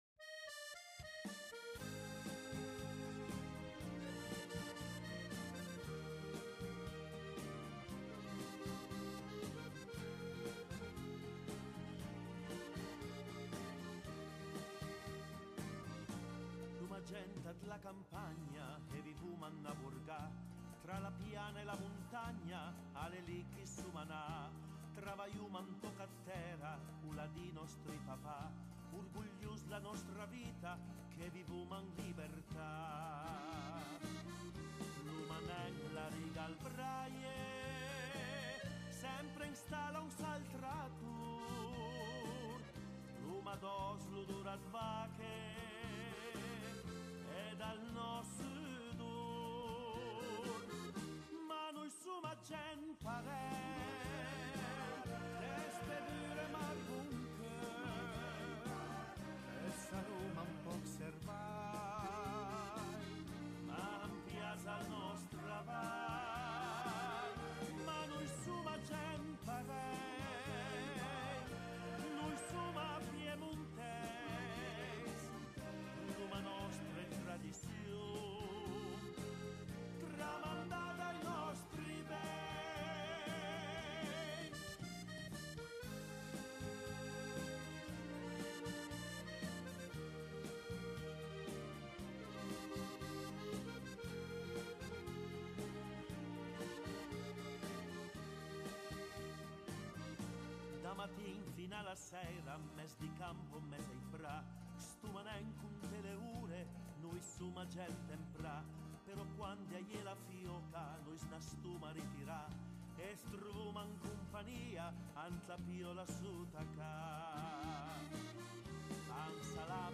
chanson piémontaise